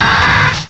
cry_not_grotle.aif